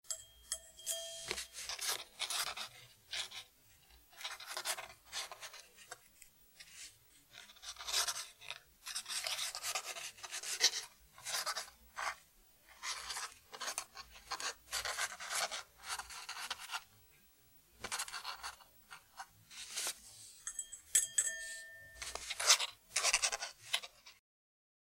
Здесь вы найдете скрип гусиного пера, шелест чернильных штрихов и металлического пера на бумаге.
Шорох старинного пера по пожелтевшим страницам